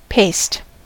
paste: Wikimedia Commons US English Pronunciations
En-us-paste.WAV